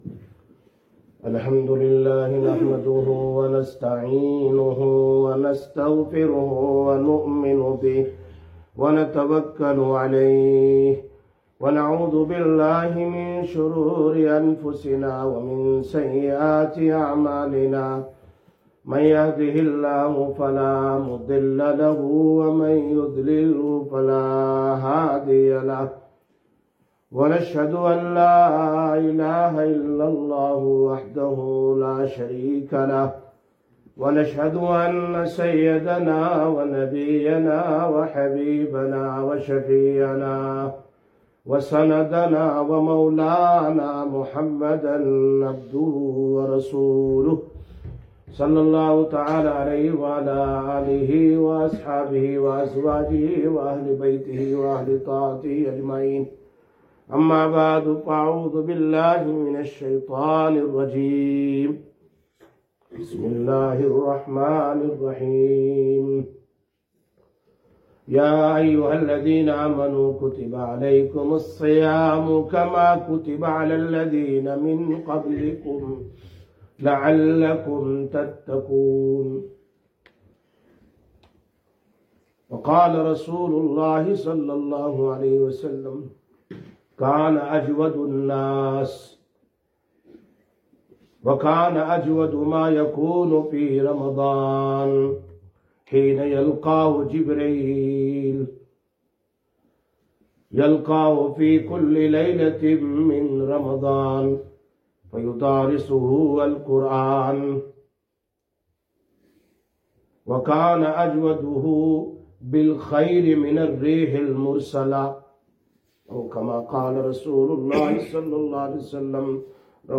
27/02/2026 Jumma Bayan, Masjid Quba